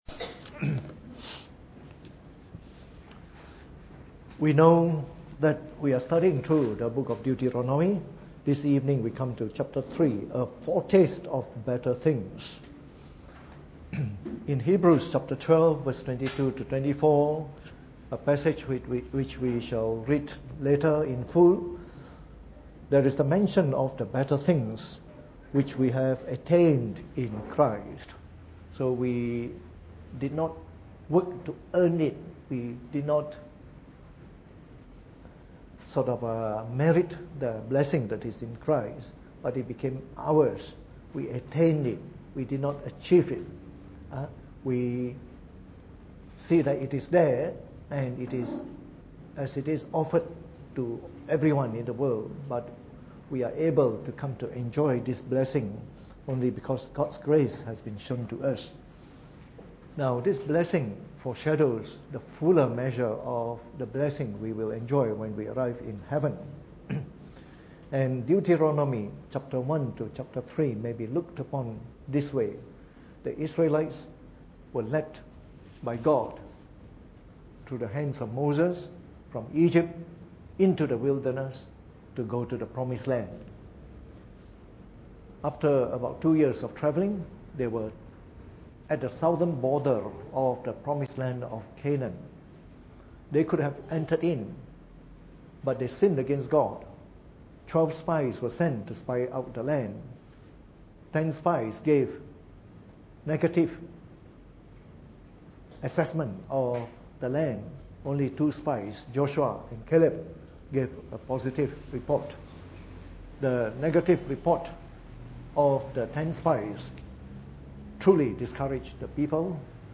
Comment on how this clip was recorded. Preached on the 24th of January 2018 during the Bible Study, from our series on the book of Deuteronomy.